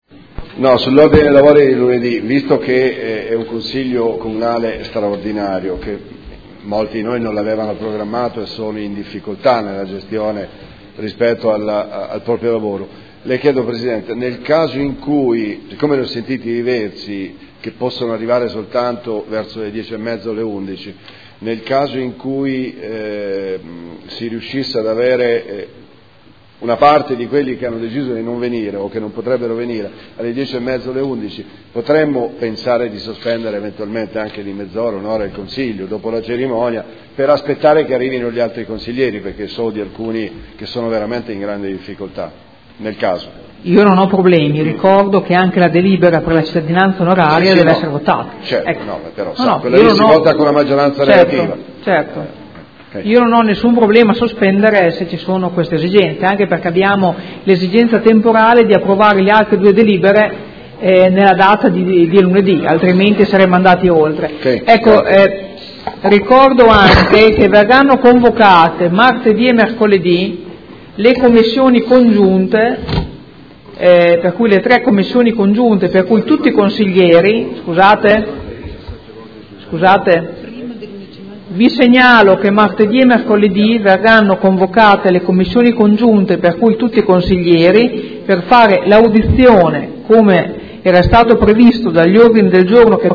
Seduta del 17/09/2015. Precisazioni riguardo le comunicazioni della Presidente sulla seduta successiva del Consiglio Comunale